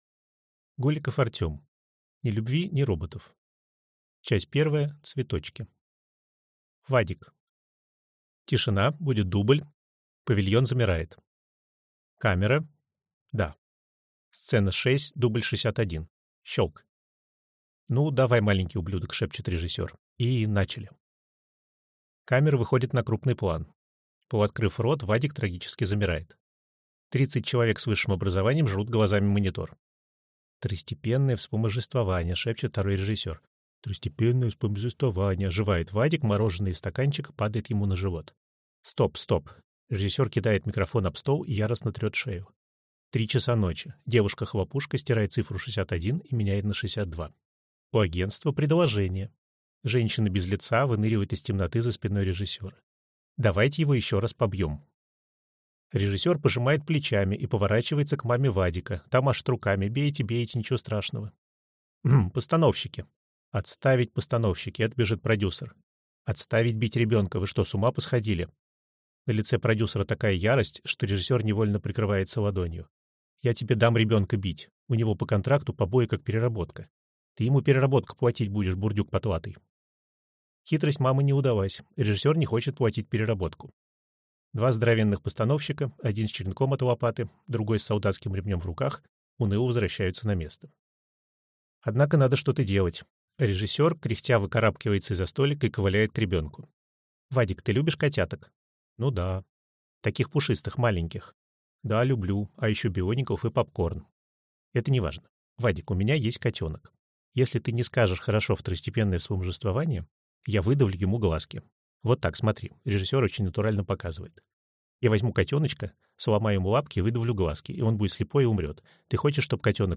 Аудиокнига Ни любви, ни роботов | Библиотека аудиокниг